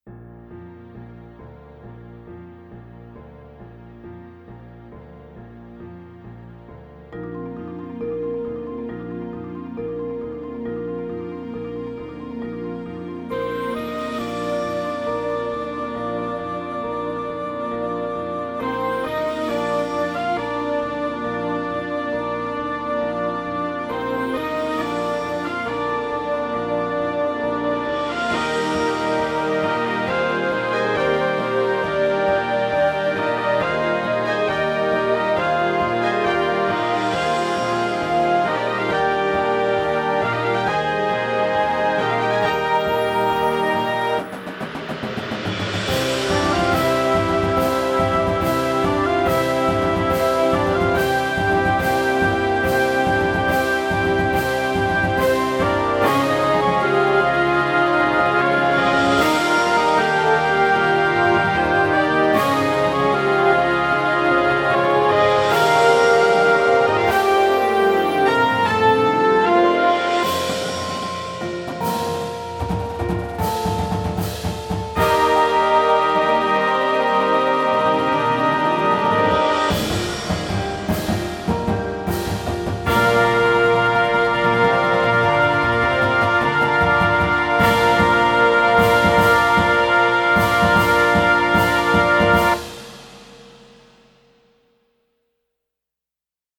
Instrumentation: Winds and Full Percussion